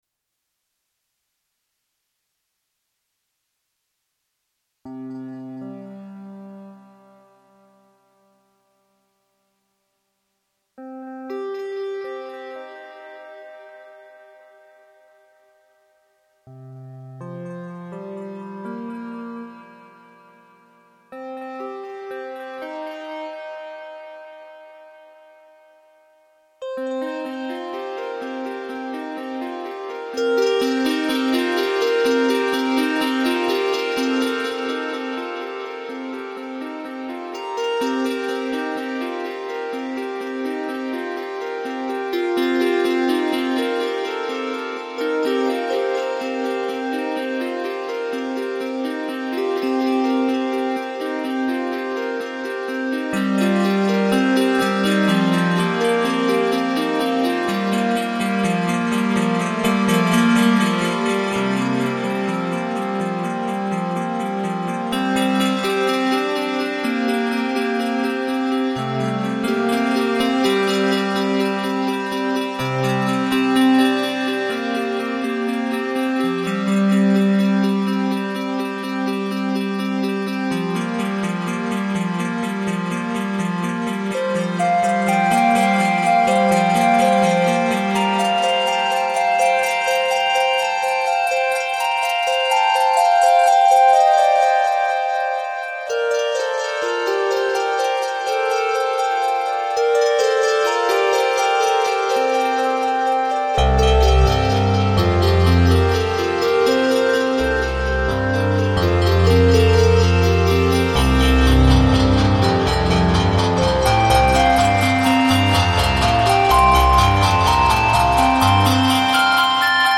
The songs are recorded with a MIDI sequencer.
5:40 - Allegretto - 23 April, 2004